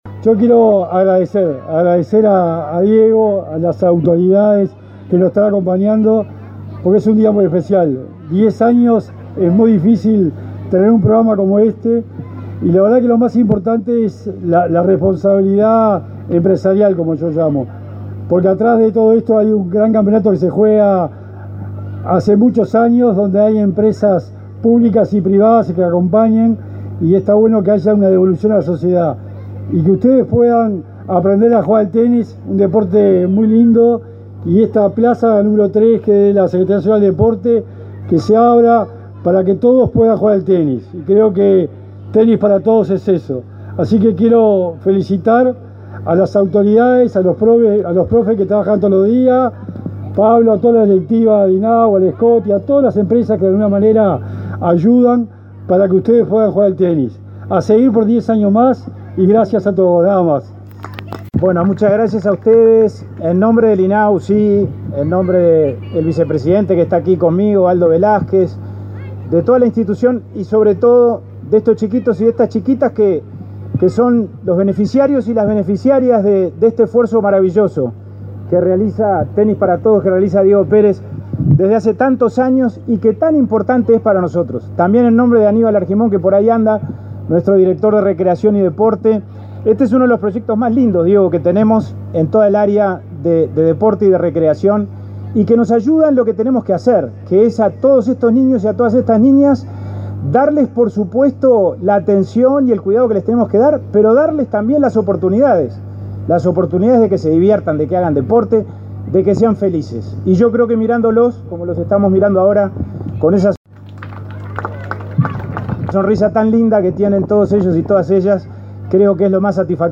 Palabras de autoridades en plaza de deportes
El secretario del Deporte, Sebastián Bauzá, y el presidente del INAU, Pablo Abdala, participaron en los festejos de los diez años del proyecto Tenis